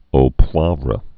(ō pwävrə)